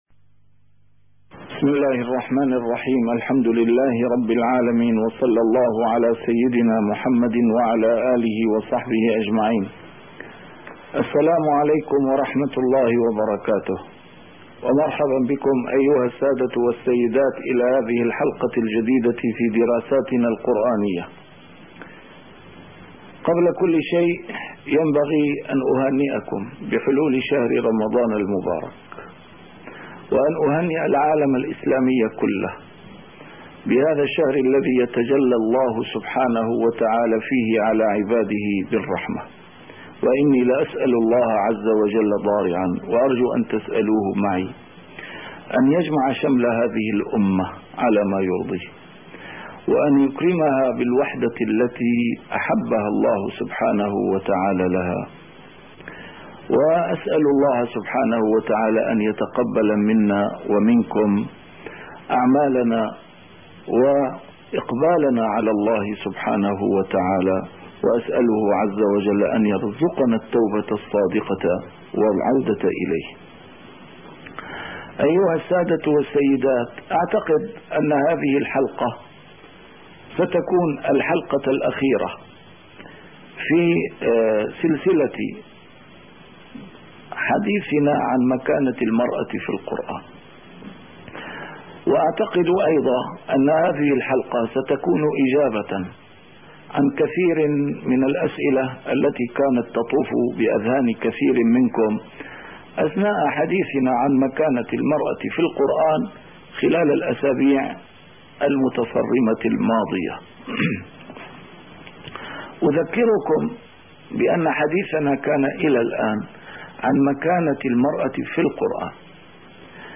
A MARTYR SCHOLAR: IMAM MUHAMMAD SAEED RAMADAN AL-BOUTI - الدروس العلمية - دراسات قرآنية - نظام الأسرة وسبل حمايتها في كتاب الله عز وجل